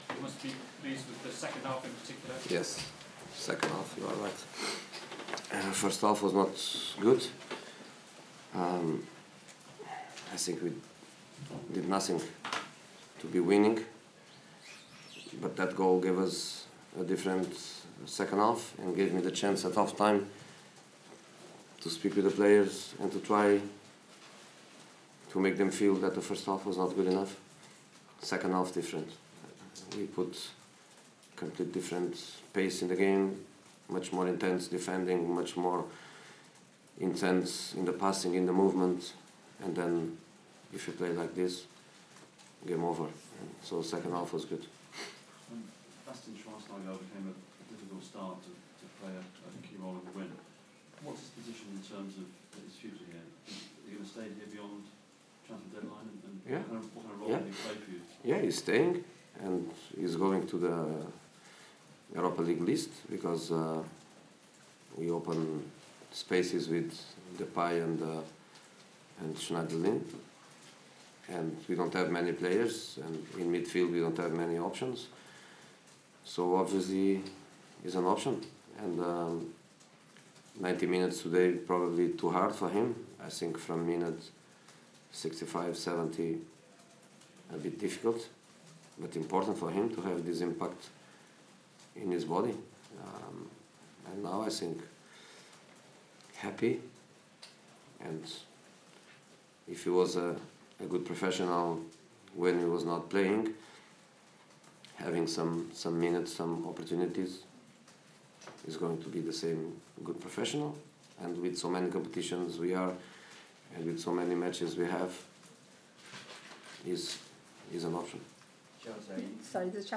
Manchester United manager Jose Mourinho speaks to the press following his side's 4-0 win over Wigan in the FA Cup at Old Trafford.